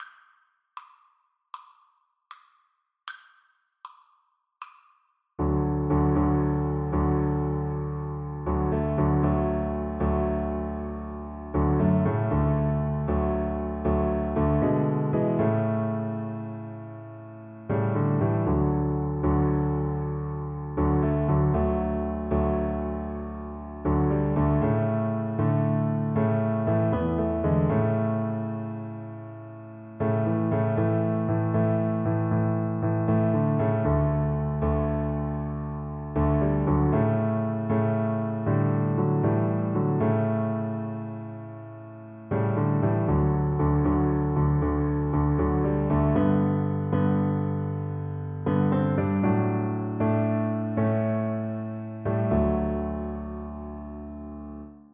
Christmas Christmas Cello Sheet Music Rolling Downward Through the Midnight
Cello
Traditional Music of unknown author.
D major (Sounding Pitch) (View more D major Music for Cello )
12/8 (View more 12/8 Music)
A3-B4